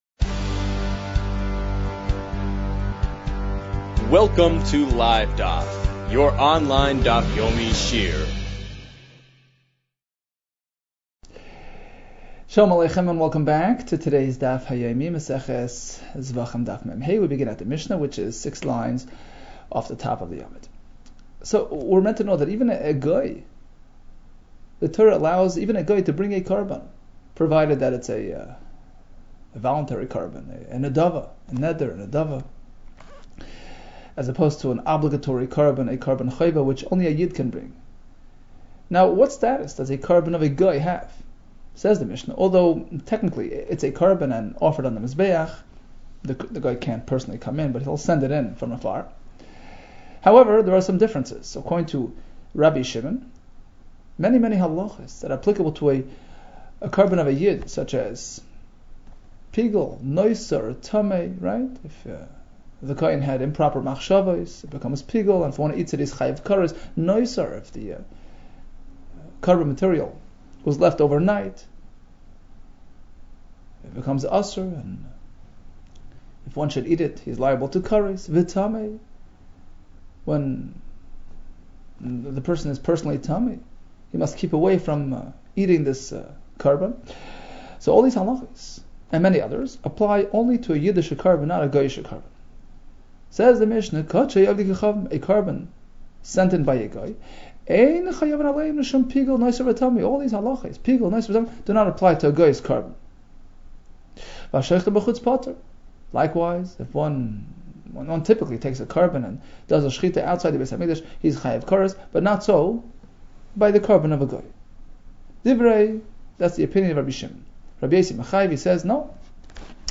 Zevachim 44 - זבחים מד | Daf Yomi Online Shiur | Livedaf